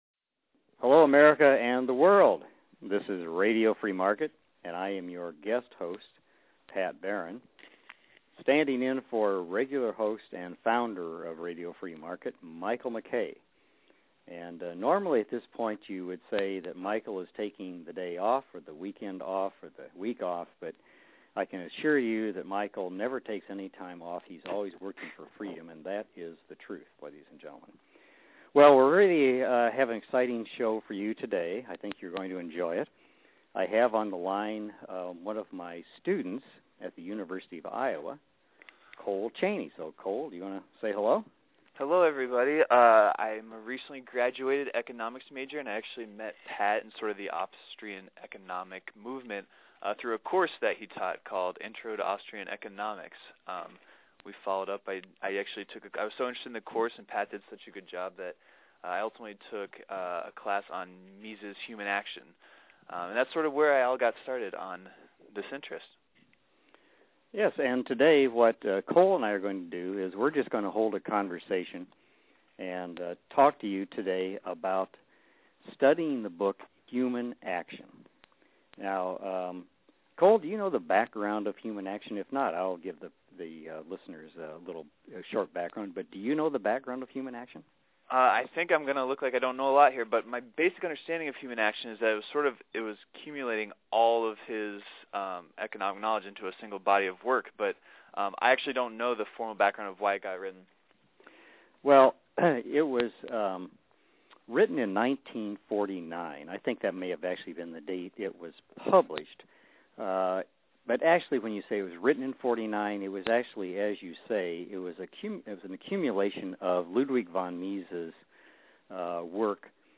a discussion of the great book Human Action